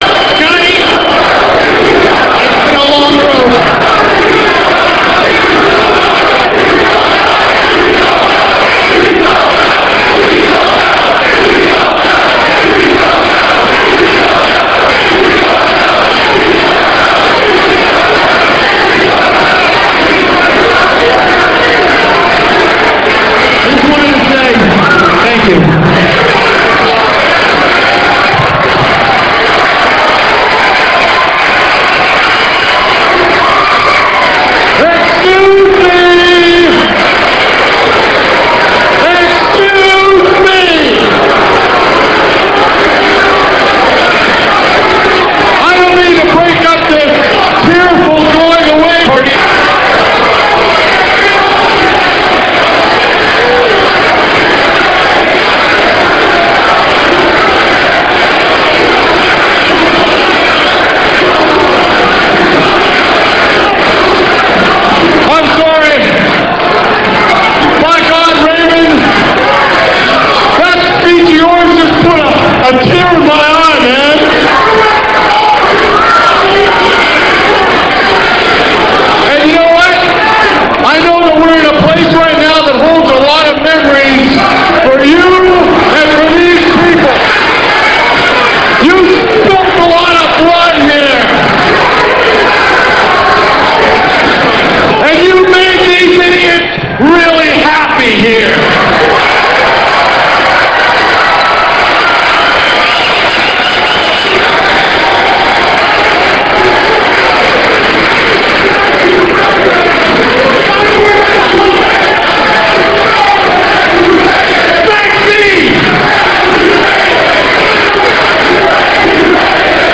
raven74.rm - This clip comes from ECW Wrestling on TNN - [07.15.00]. Raven says his goodbyes to ECW as he heads to WWF, but is intercepted by Cyrus who claims Raven is a ripoff of his Jackyl persona in WWF.